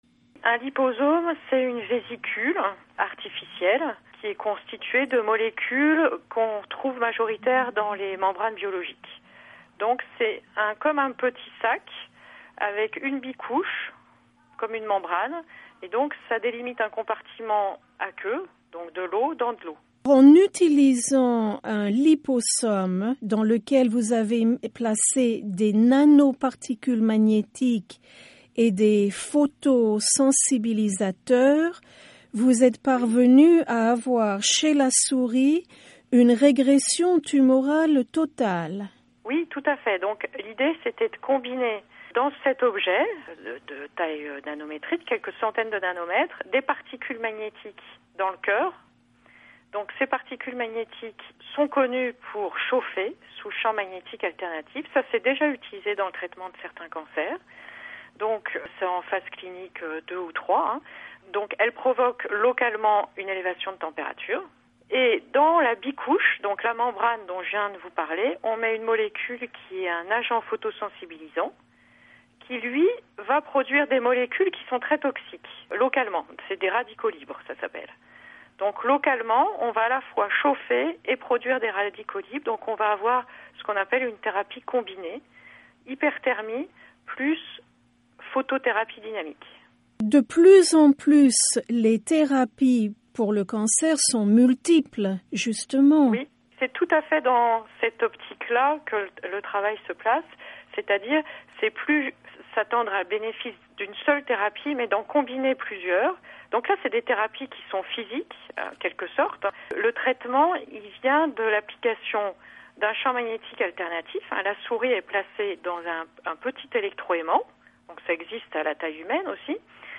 dans un entretien avec la Voix de l'Amérique.